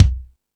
Lotsa Kicks(17).wav